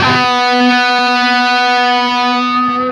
LEAD B 2 CUT.wav